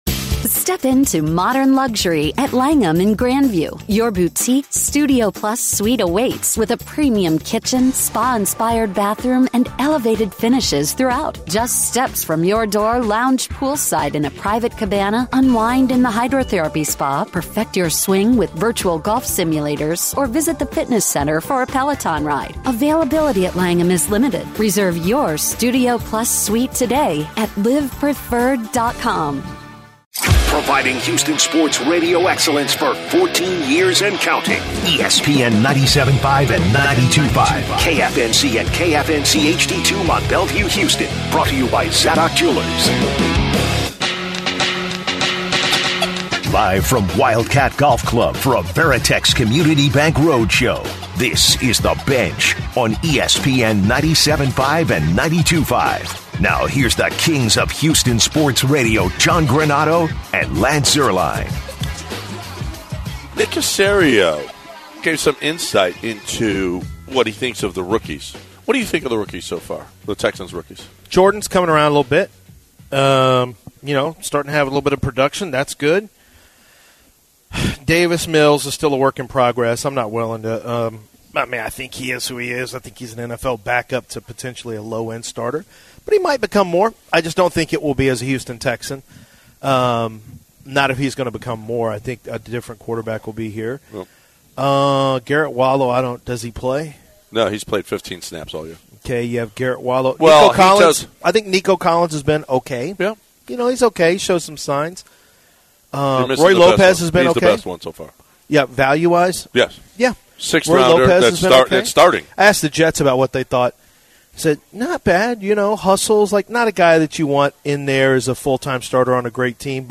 The Bench is LIVE from Wildcat Golf Club for the ESPN 97.5 Occasional Invitational!